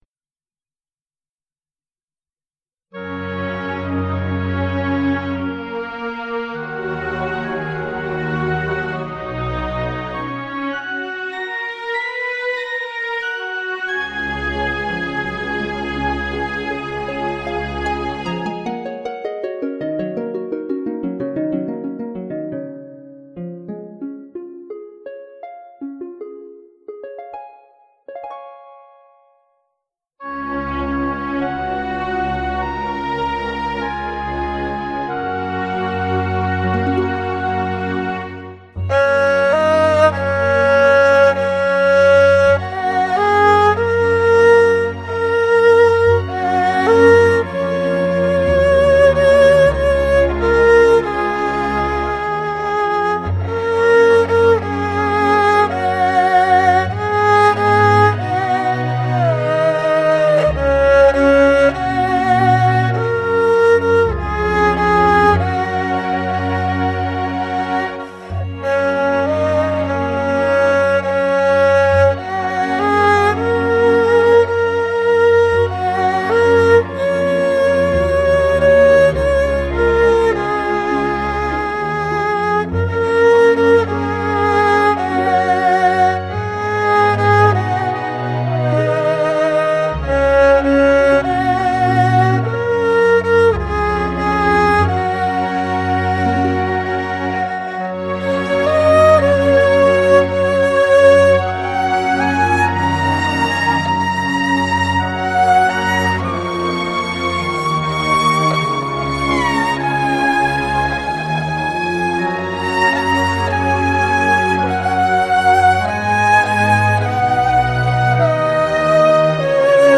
这次回来，请了一把“高大上”的二胡，标价700元人民币，最终给了个友情价600元，感觉效果好多了。
二胡曲